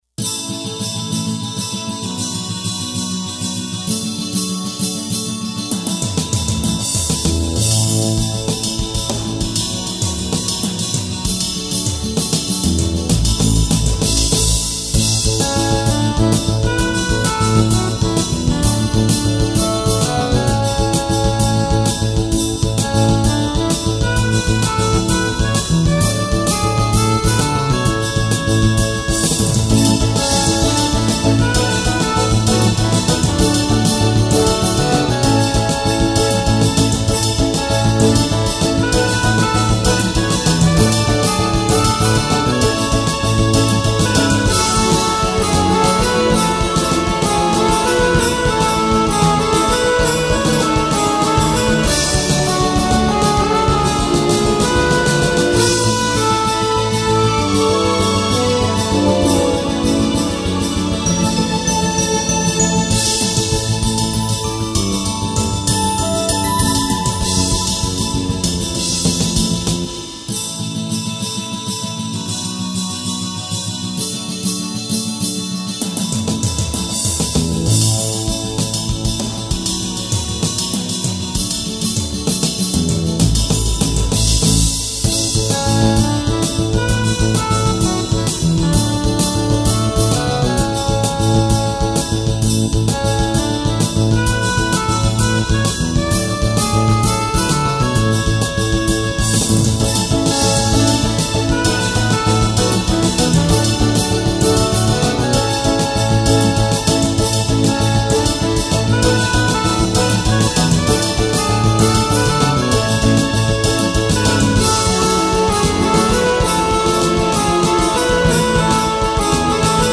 こちらでは、ゲーム内で使われているＢＧＭを何曲か、お聴かせしちゃいます。
穂澄のイメージテーマ。